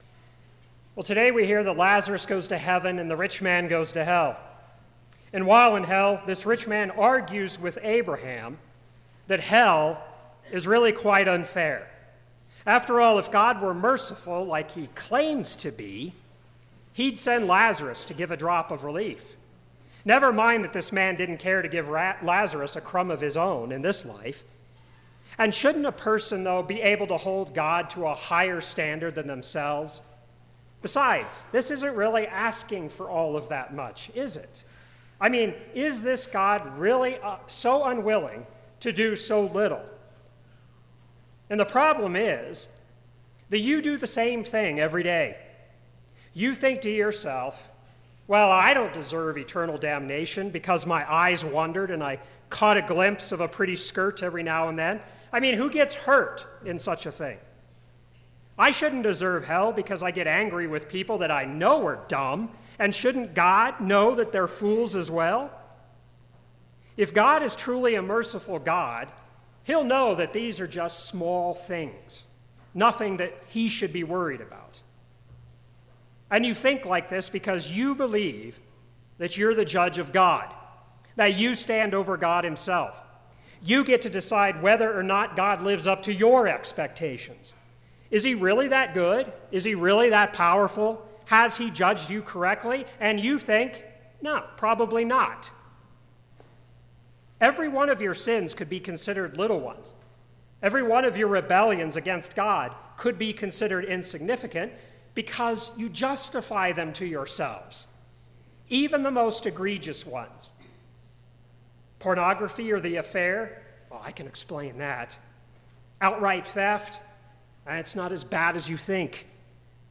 First Sunday after Trinity
Sermon – 6/3/2018
Sermon_June3_2018.mp3